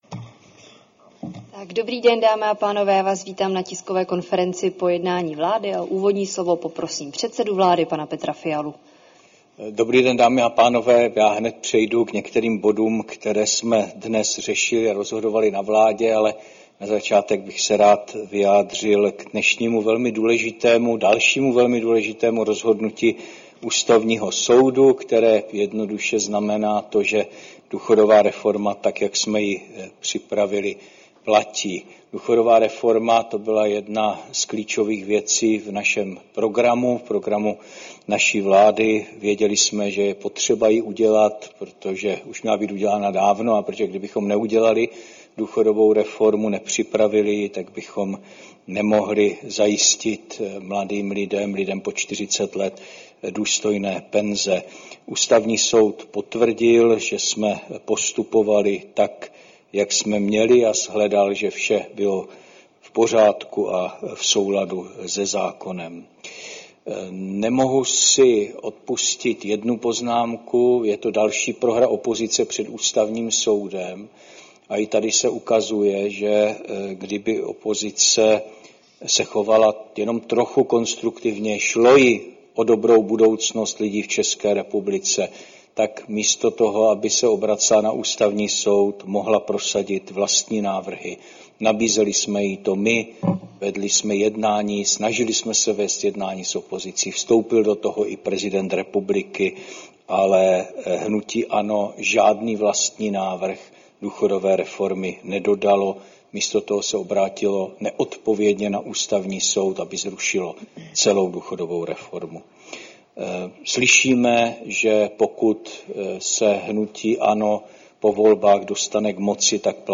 Tisková konference po jednání vlády, 9. července 2025